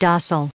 245_docile.ogg